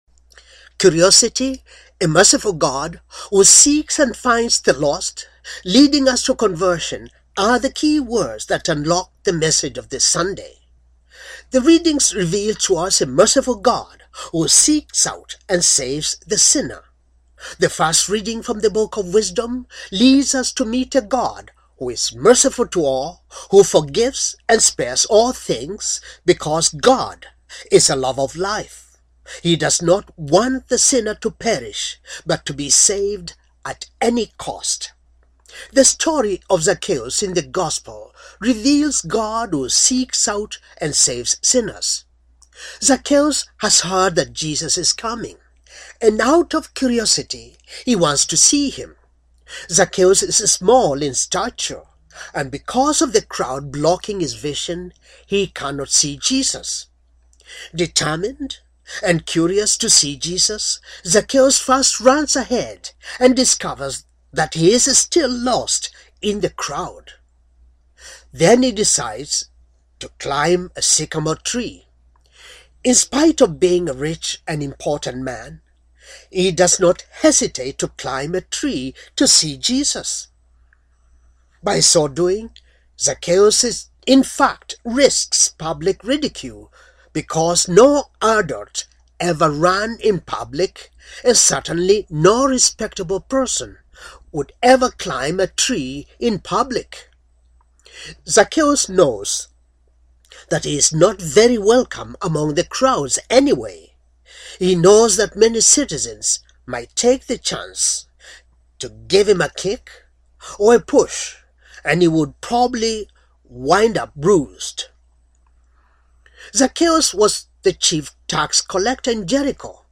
Homily, thirty first, Sunday, ordinary, time, year c